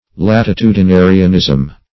latitudinarianism - definition of latitudinarianism - synonyms, pronunciation, spelling from Free Dictionary
Latitudinarianism \Lat`i*tu`di*na"ri*an*ism\, n.